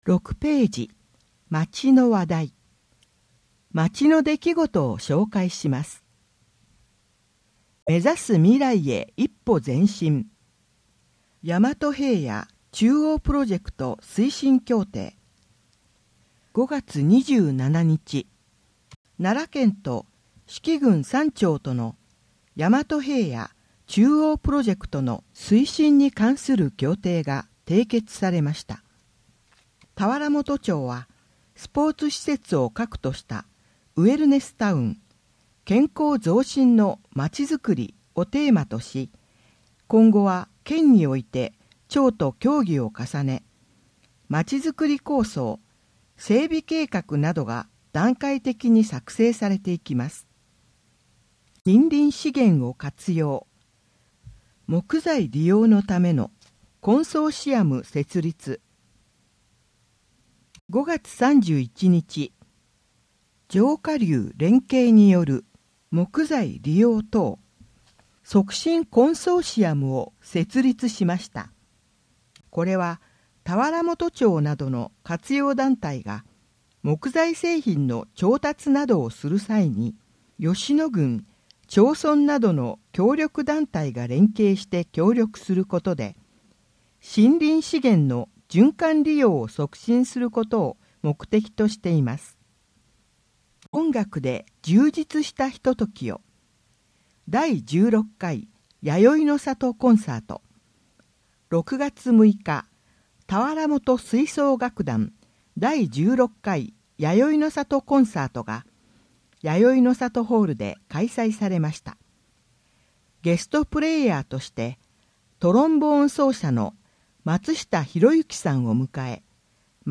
音訳広報たわらもと
音訳広報たわらもと4～5ページ (音声ファイル: 2.9MB)